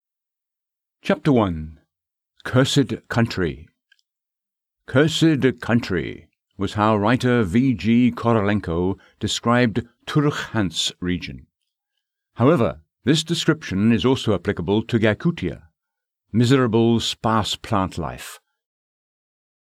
Issue: The submitted files do not meet our noise floor requirement – All files contain buzz
I don’t hear (or see) any “buzz” in that sample, though I can hear the Noise Reduction effect
It is below -60 dB, which is what ACX specify, but it is “suspiciously” low. Nobody gets that low without processing the noise out.
There is the “Blackness Of Space” silence between each word and in some cases I can hear odd tails on the words.